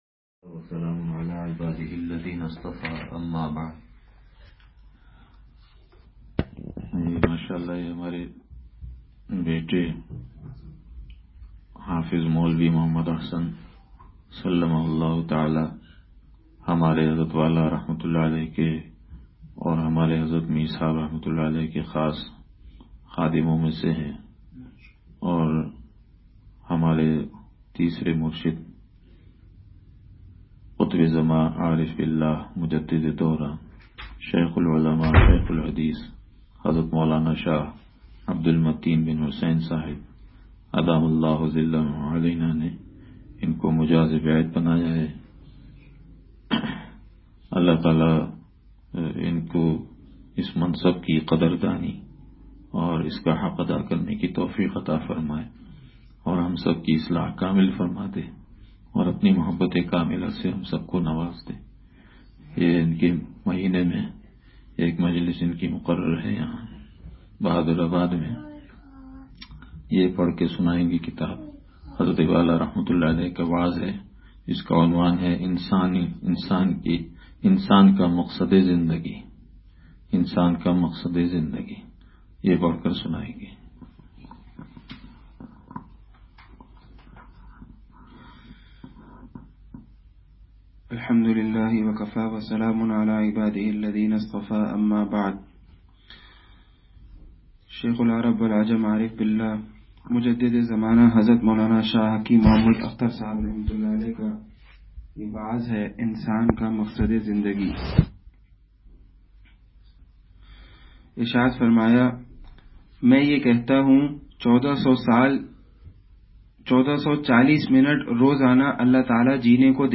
بیان – کوکن